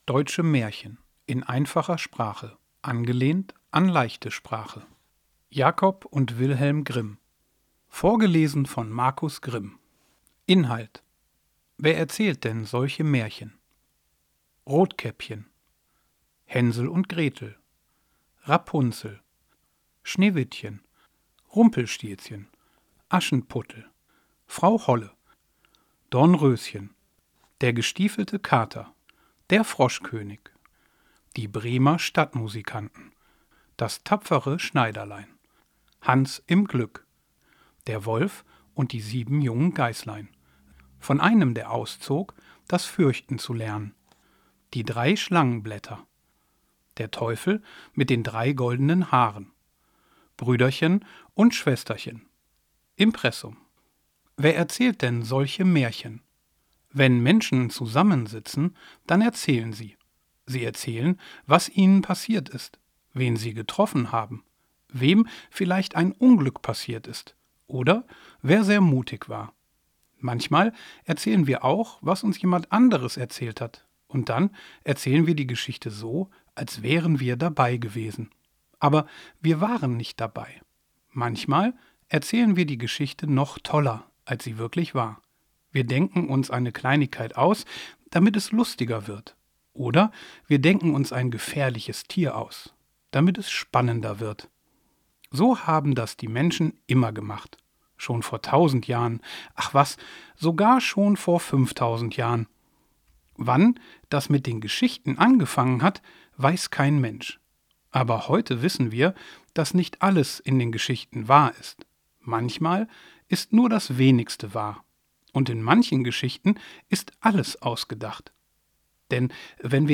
Audio/Hörbuch